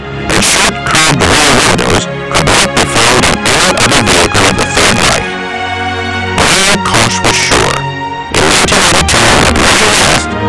[linux-dvb] HVR-1300 Audio Issues. Audio works but is way too loud
My input is from my cable box into the composite in of the HVR-1300.
The video is fine but the audio is way too loud.